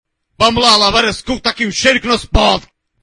mae do ruca vamos la lavar o cu Meme Sound Effect
Category: Reactions Soundboard